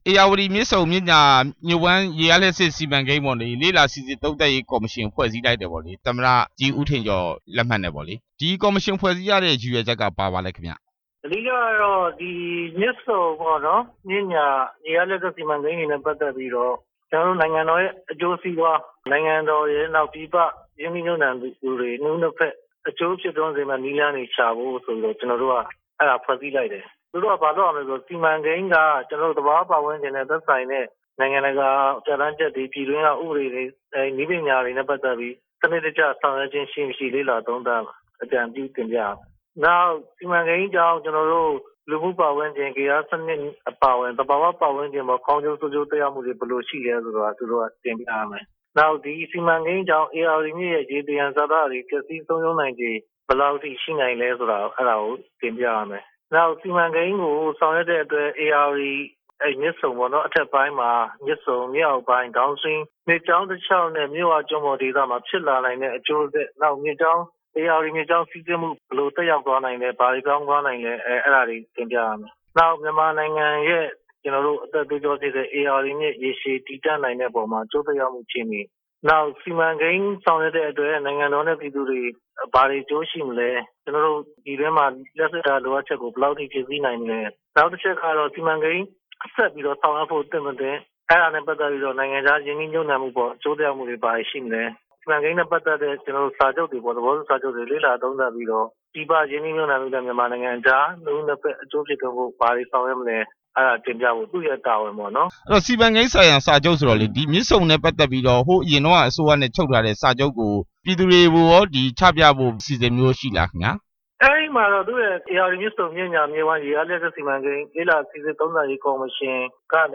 ဦးဇော်ဌေး ကို ဆက်သွယ်မေးမြန်းချက်